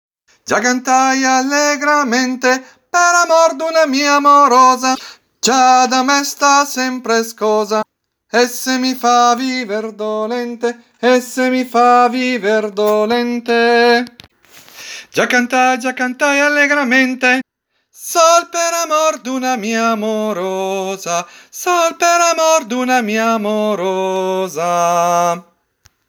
TENORI